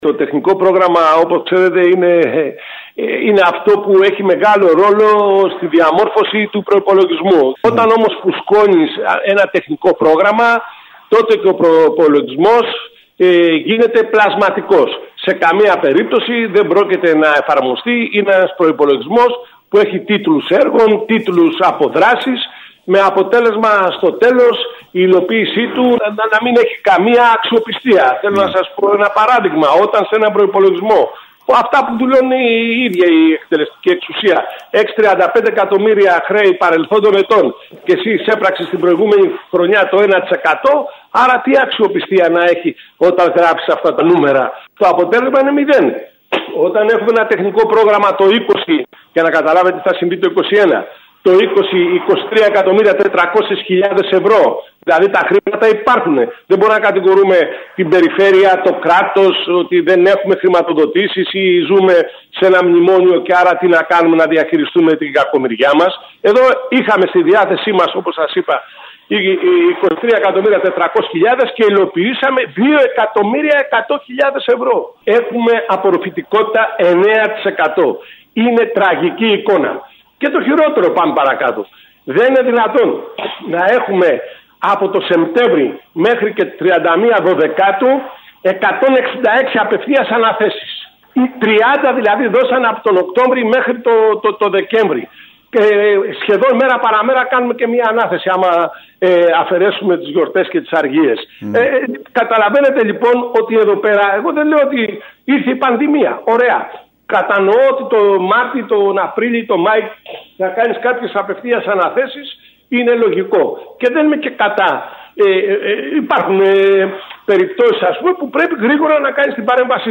Την άρνηση της παράταξής του να ψηφίσει τον δημοτικό προϋπολογισμό και το πρόγραμμα τεχνικών έργων του Δήμου Κεντρικής Κέρκυρας, δικαιολόγησε μιλώντας στο σταθμό μας, ο επικεφαλής της μείζονος μειοψηφίας στο Δημοτικό Συμβούλιο, Γιάννης Τρεπεκλής.